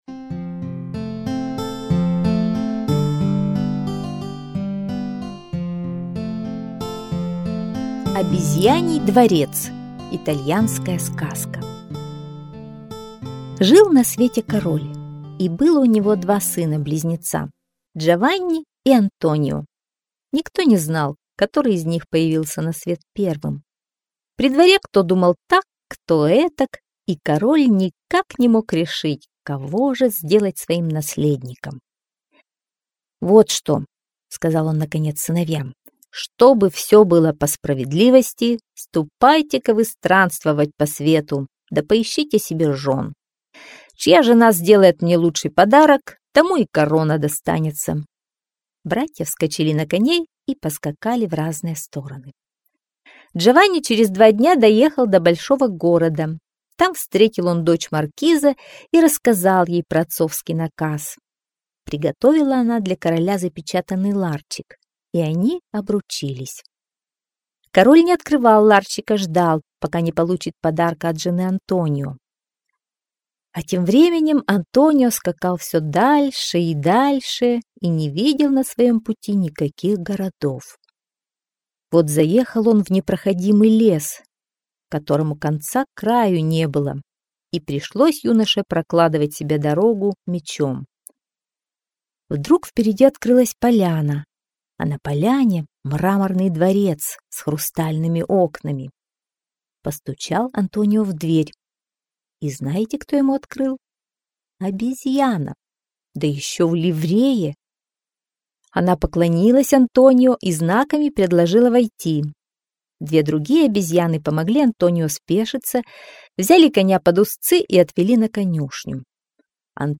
Обезьяний дворец - итальянская аудиосказка - слушать онлайн